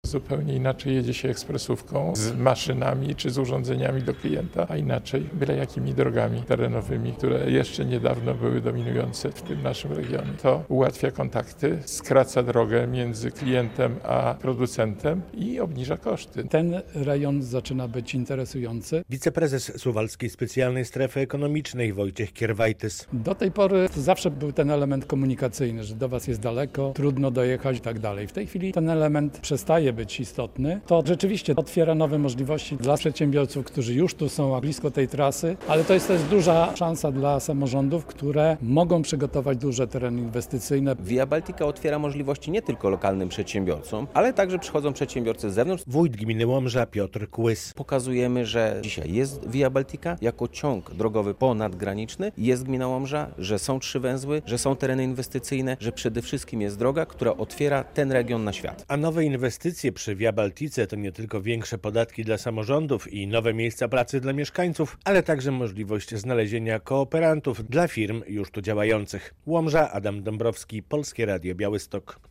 Via Baltica to "Nowe horyzonty inwestycji" i "Szansa na rozwój małych i średnich przedsiębiorstw" - przekonywano podczas konferencji pod takimi hasłami, którą zorganizował samorząd gminy Łomża.
relacja